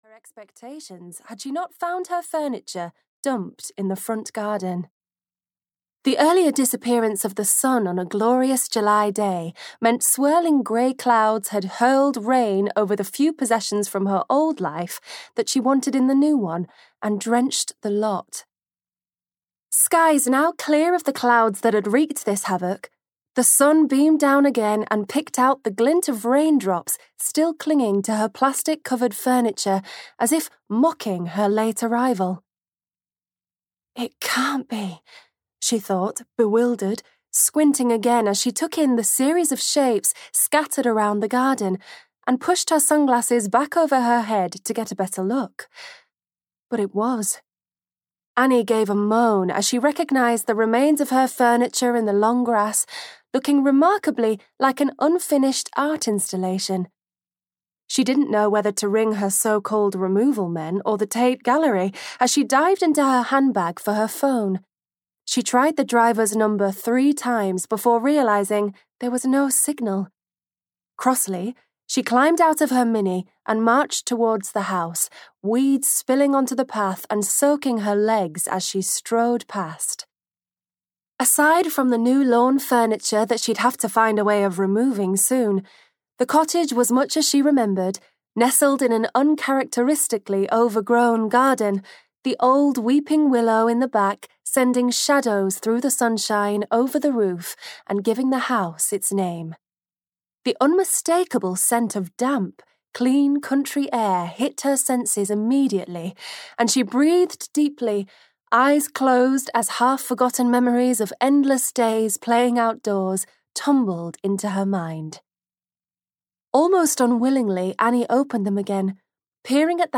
The Cottage of New Beginnings (EN) audiokniha
Ukázka z knihy